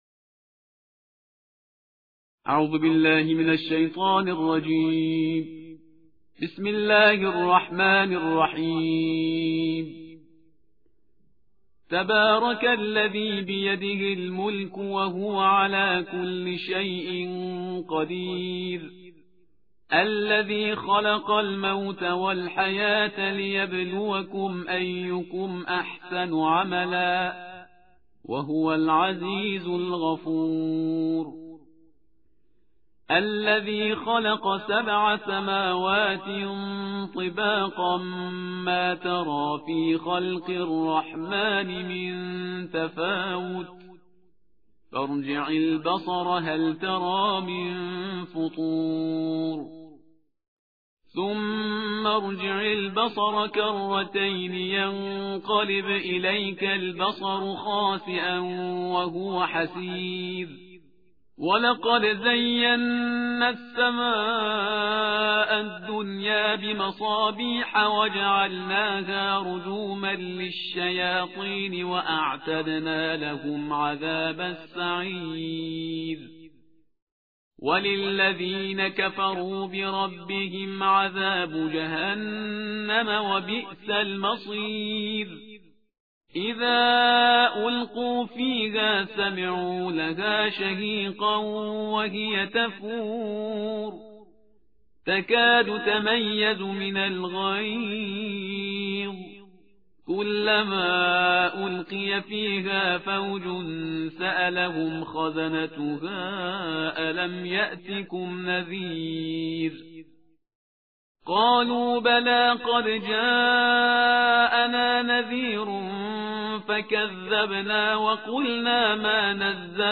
ترتیل جزءبیست و نه قرآن کریم/استاد پرهیزگار